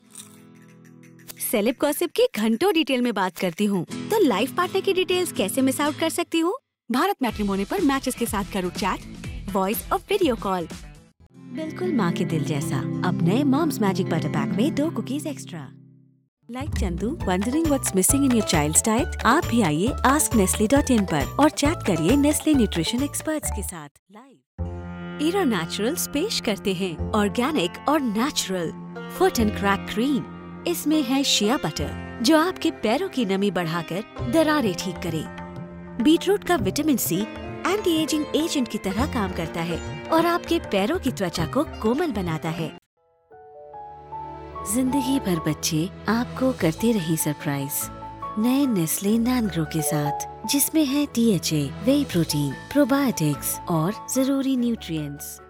A Storyteller | Voice Artist | WARM, HONEST and BELIEVABLE voice for your brand | let's discuss your story | Broadcast quality professional studio setup | Equipped with SOURCE CONNECT| Recording for any studio globally
Hindi Commercial VO